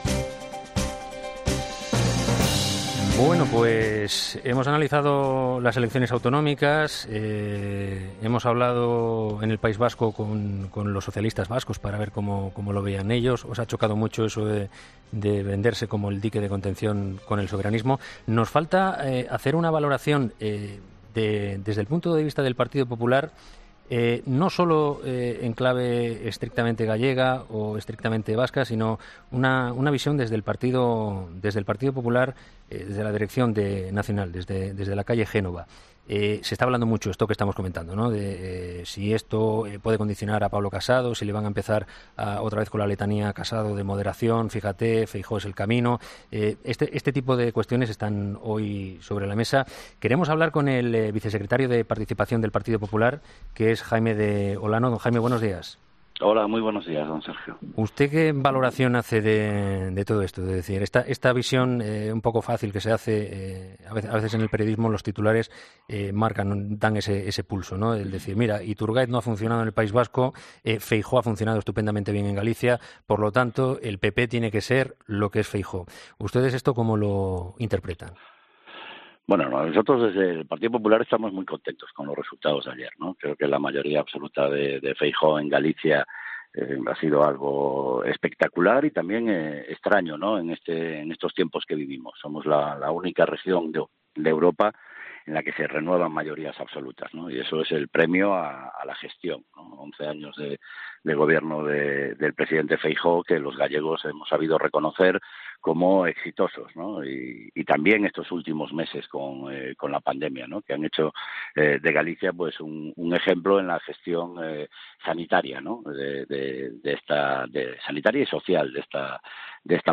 [ESCUCHA AQUÍ LA ENTREVISTA A JAIME DE OLANO EN "HERRERA EN COPE"]